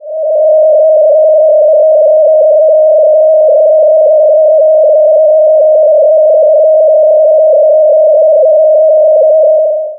音響化: κ→音程（MIDI 50-80）、r_t→音量（-30～-6dB）
κ呼吸の長周期性を音響化したサウンドです。音程はκ値、音量はr_t（生成活動）に対応しています。
eta_cosmos_breath.wav